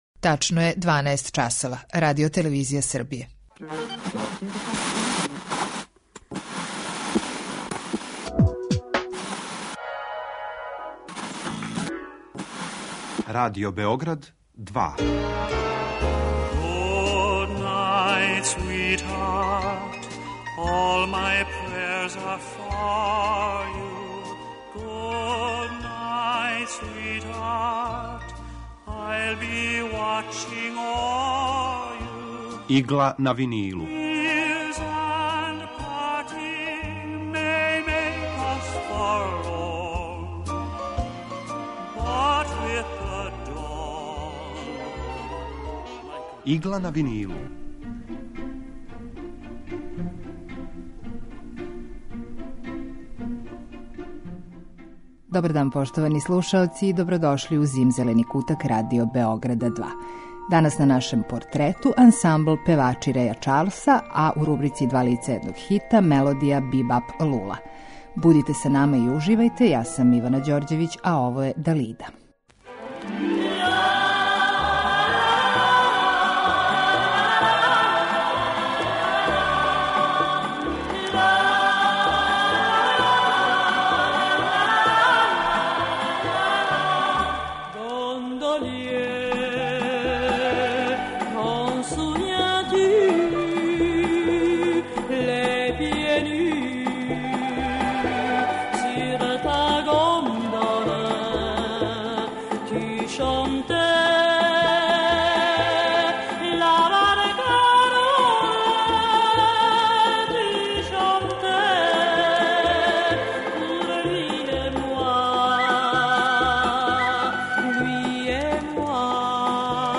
Евергрин